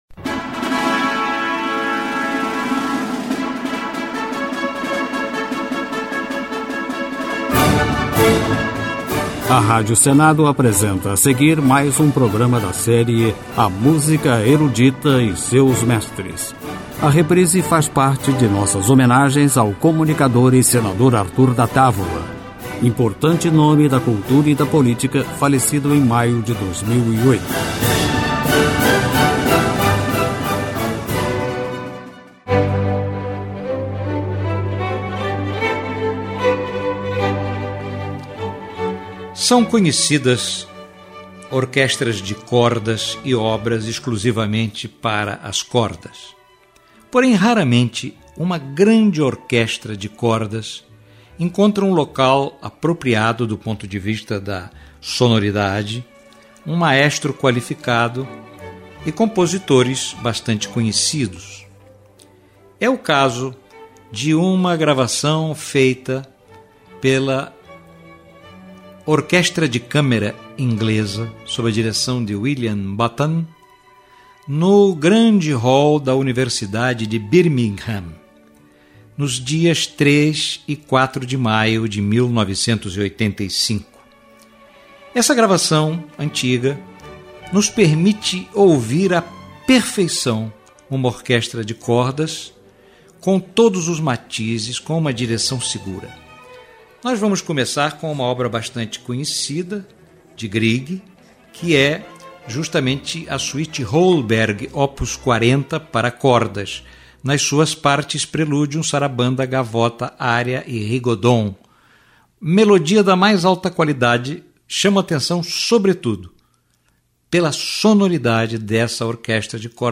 Música clássica